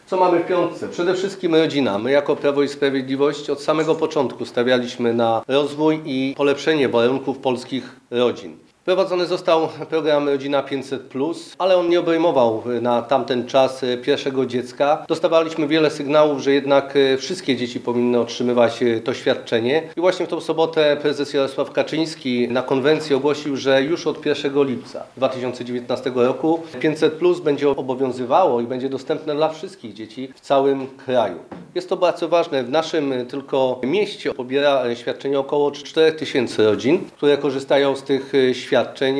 Jak mówi poseł Wojciech Kossakowski, skorzystają z tego zwłaszcza mieszkańcy Mazur.